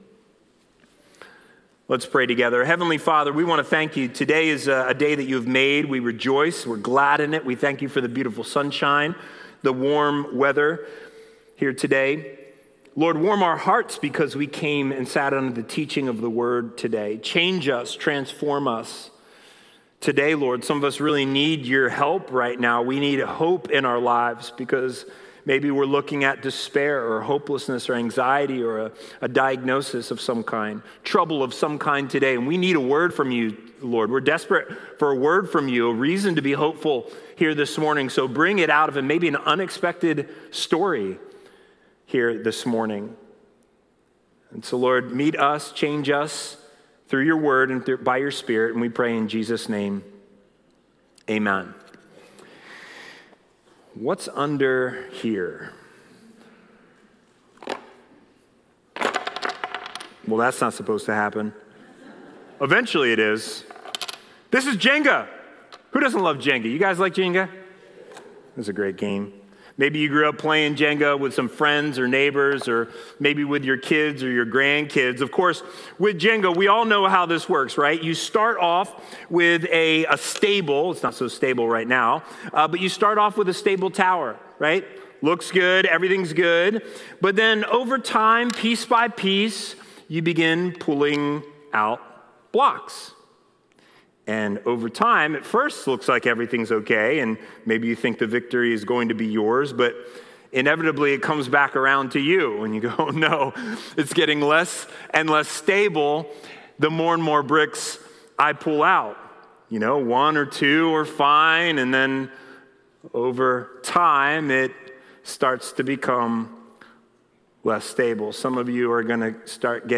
Watch previously recorded Sunday sermons.